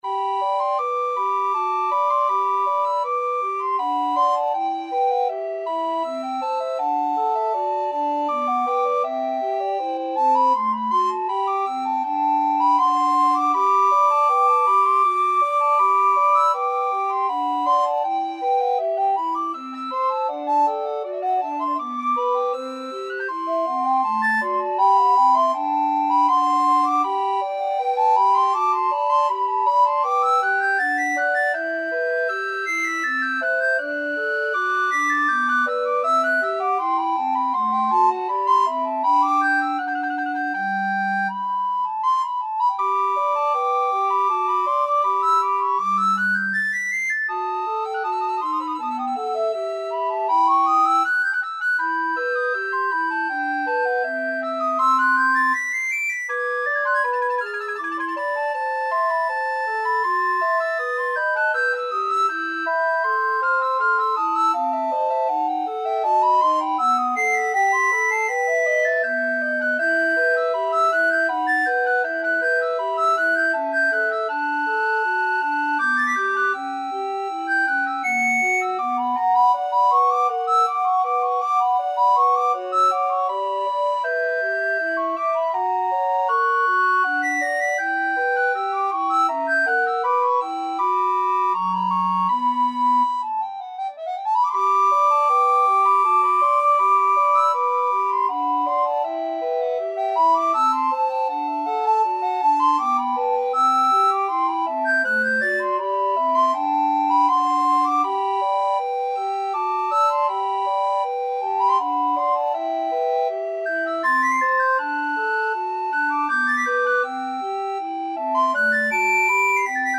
3/4 (View more 3/4 Music)
Andante
Classical (View more Classical Recorder Trio Music)